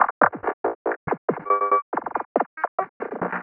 tx_perc_140_robots1.wav